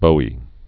(bōē, bē)